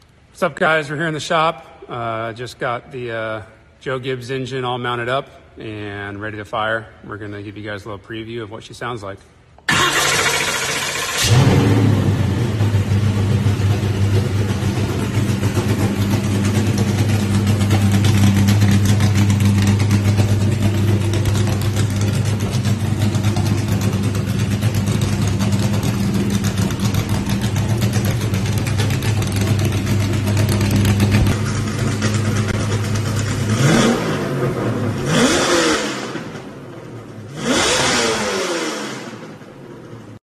SOUND ON 🔊 This engine sound effects free download
SOUND ON 🔊 This engine definitely barks.